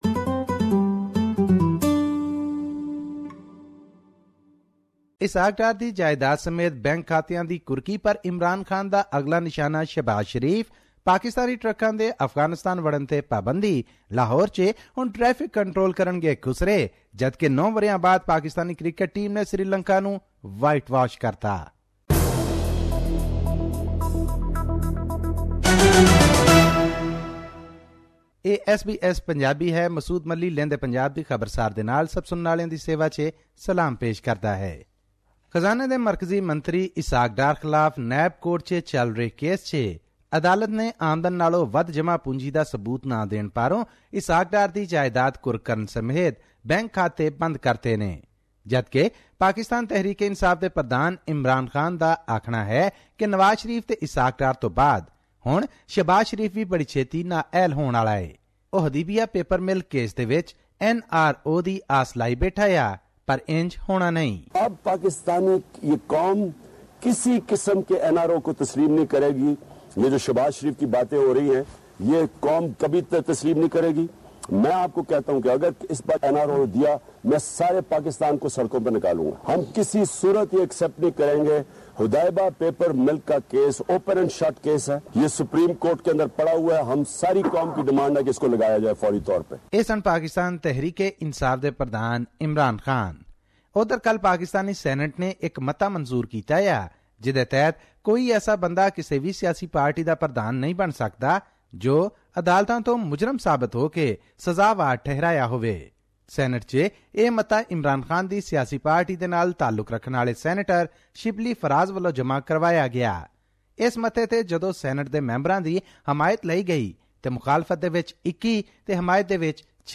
Political situation in Pakistan is taking fast turns as the legal system is ordering some inquiries on political figures. Also there are some changes on the trucks and goods that come from Afghanistan into Pakistan. Listen to Pakistan Punjabi report